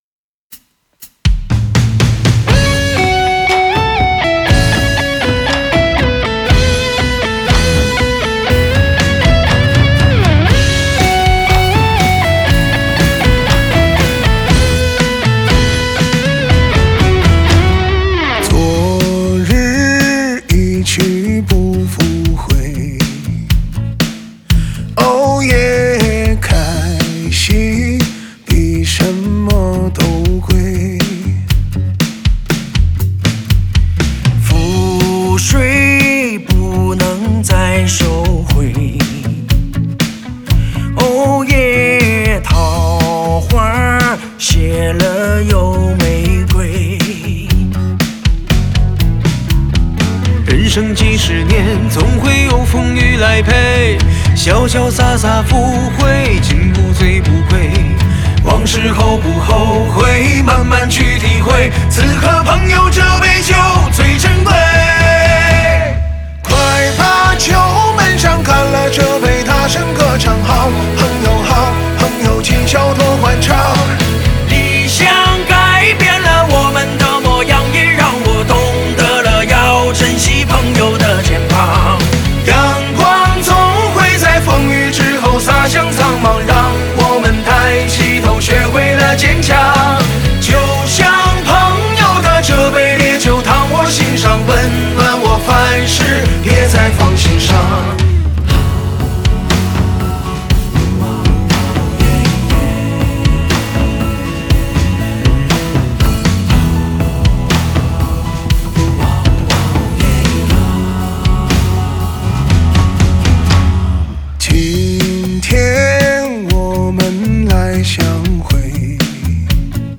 Ps：在线试听为压缩音质节选，体验无损音质请下载完整版
吉他
贝斯